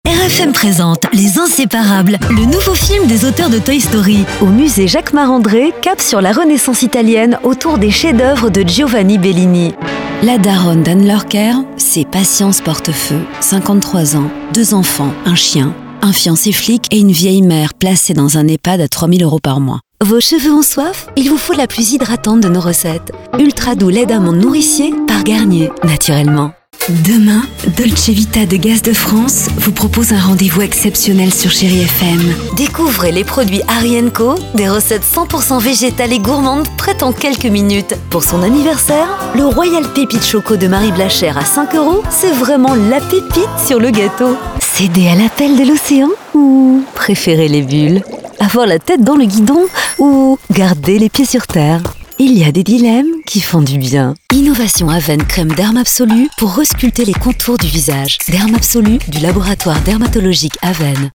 Comercial, Natural, Travieso, Versátil, Empresarial
Comercial